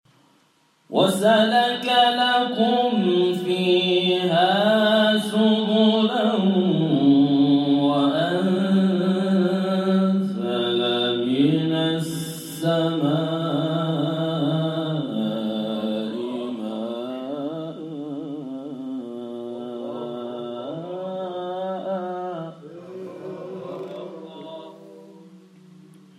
گروه شبکه اجتماعی: نغمات صوتی از تلاوت قاریان بین‌المللی و ممتاز کشور که به تازگی در شبکه‌های اجتماعی منتشر شده است، می‌شنوید.